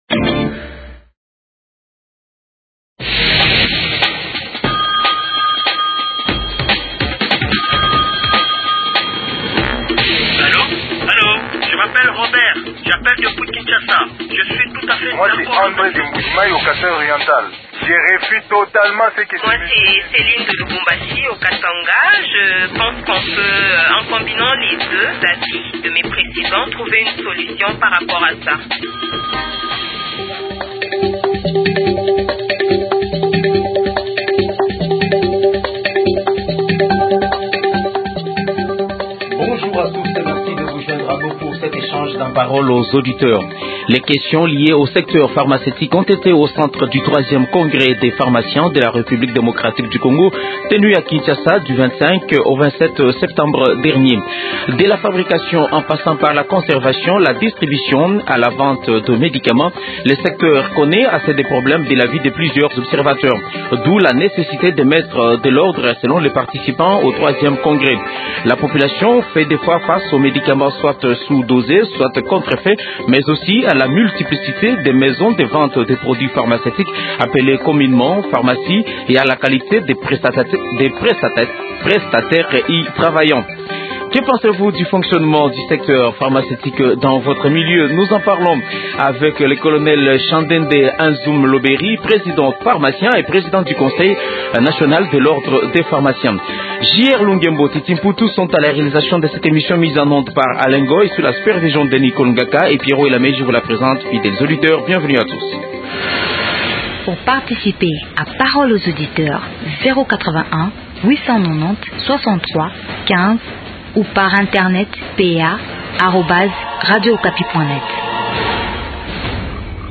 Télécharger Thème de jeudi 11 octobre On se retrouve vendredi pour un autre numéro de Parole aux auditeurs.